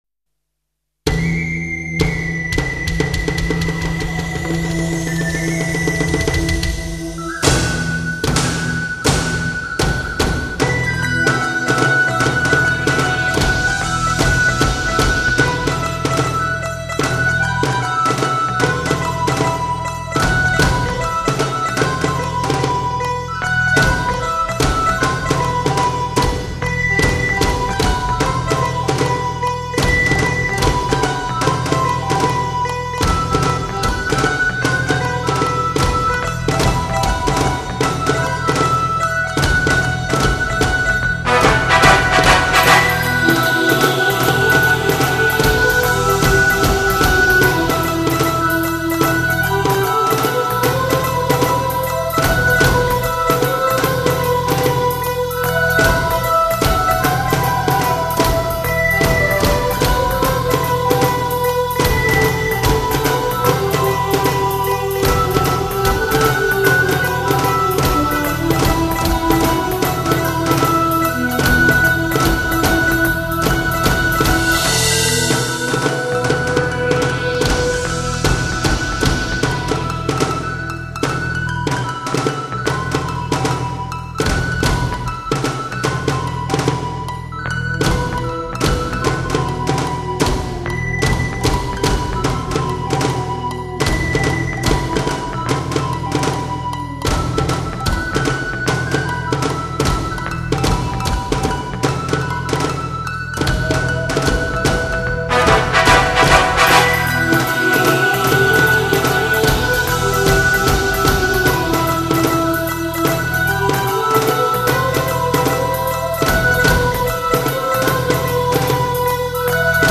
(吉祥姫演奏：最後が早くなる　)